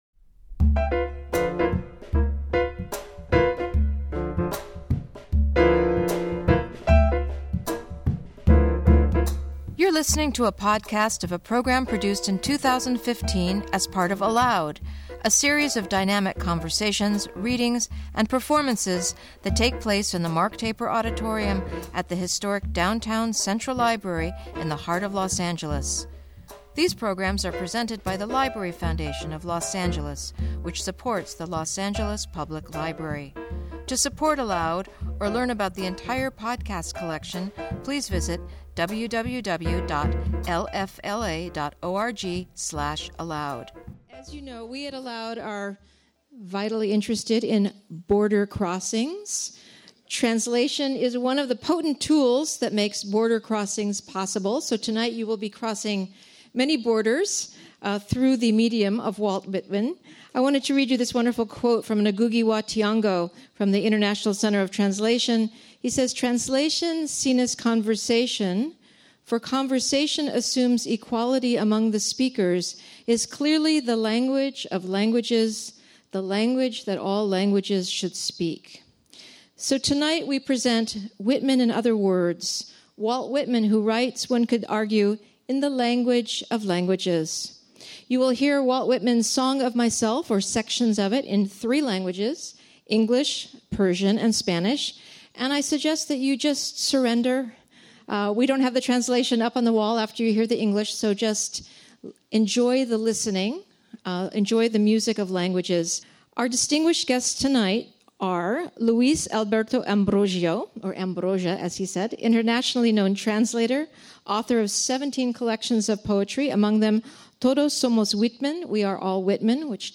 Trilingual reading and conversation
Musical Performance